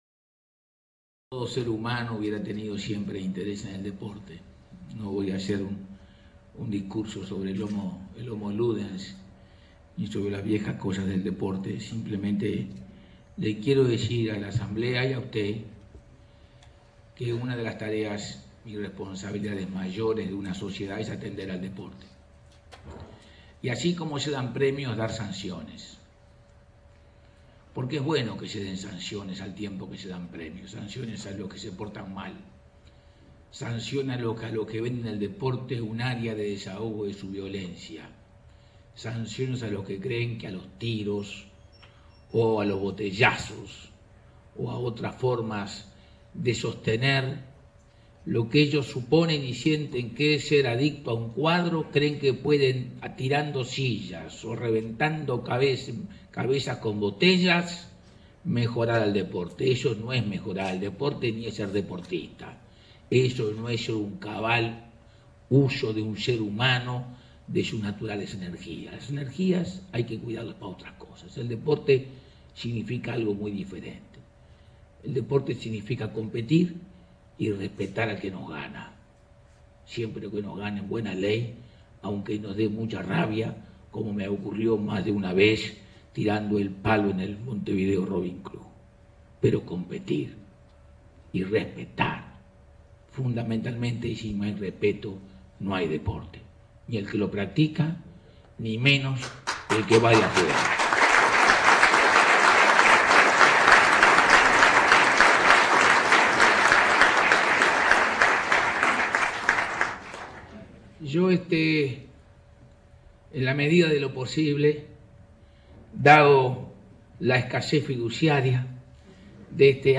Discurso del Pte. Jorge Batlle entrega de premios a Deportistas del Año, por el C.O.U.
E. Libertad. 04 de mayo de 2000.